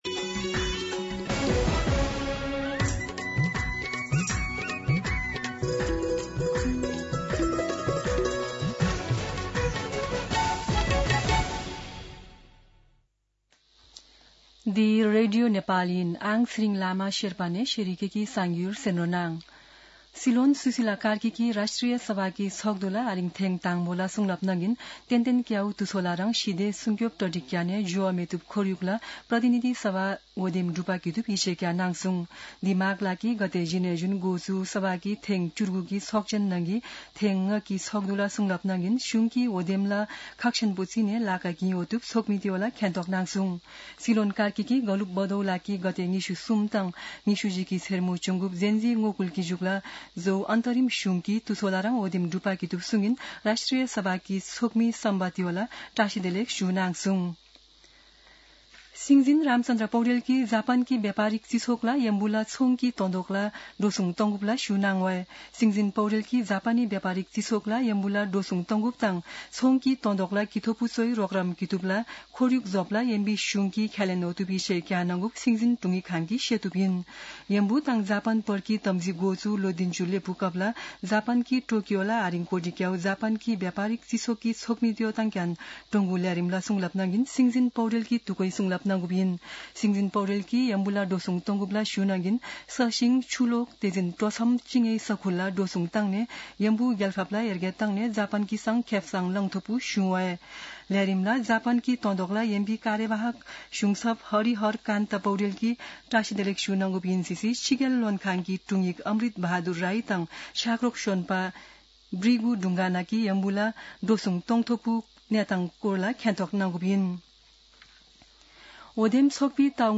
शेर्पा भाषाको समाचार : १९ माघ , २०८२
Sherpa-News-19.mp3